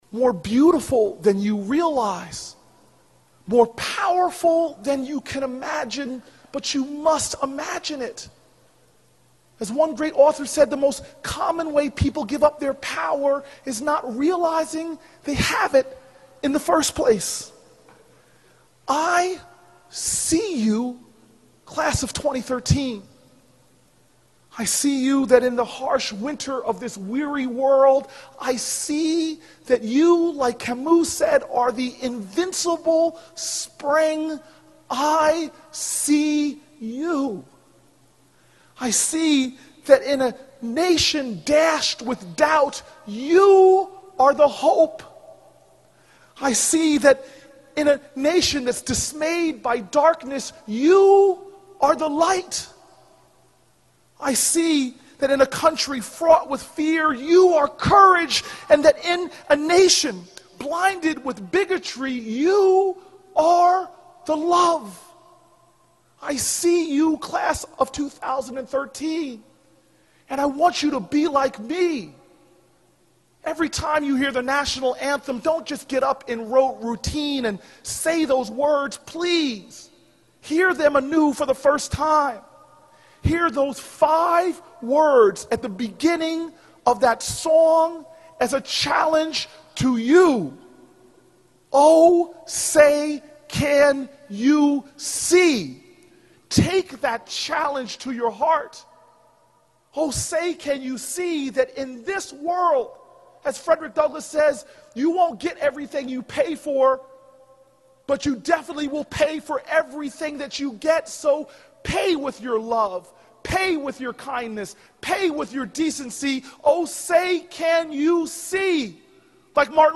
公众人物毕业演讲 第455期:科里布克2013年耶鲁大学(23) 听力文件下载—在线英语听力室